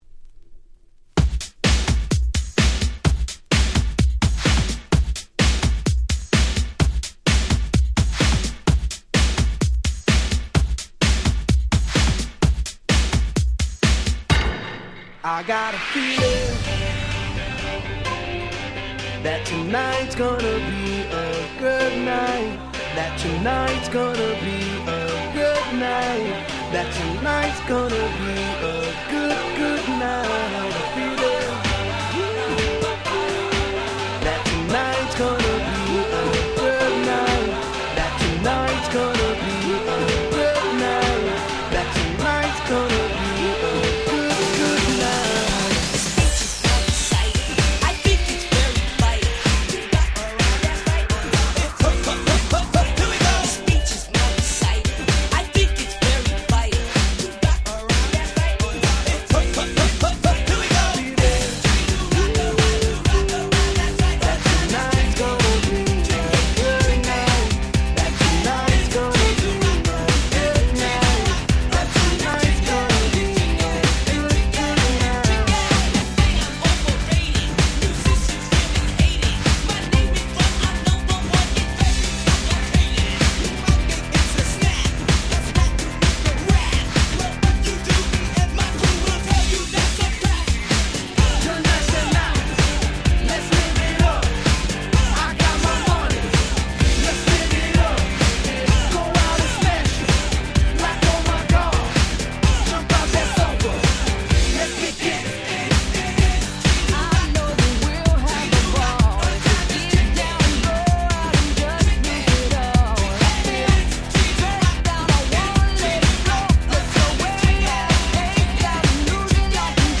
みんな大好き、超絶アゲアゲクラシック！
EDM アゲアゲ パリピ キャッチー系